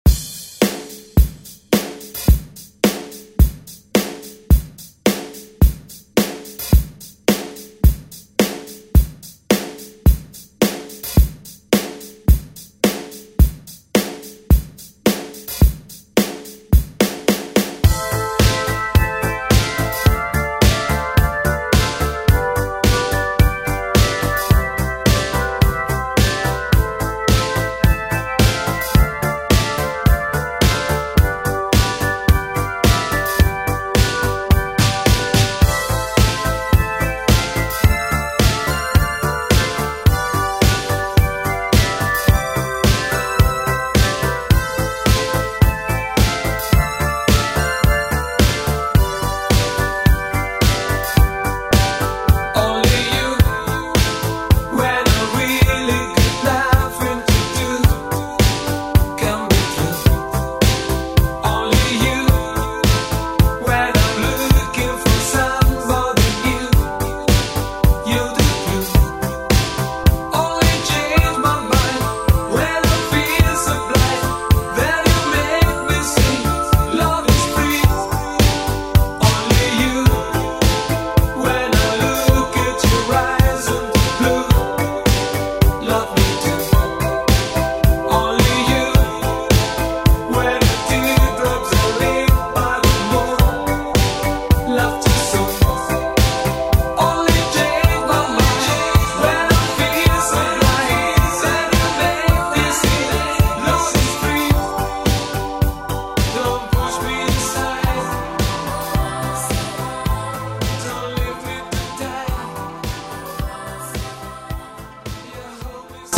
BPM: 108 Time